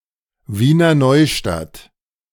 Wiener Neustadt (German pronunciation: [ˈviːnɐ ˈnɔʏʃtat]